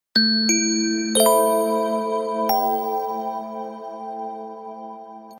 एसएमएस रिंगटोन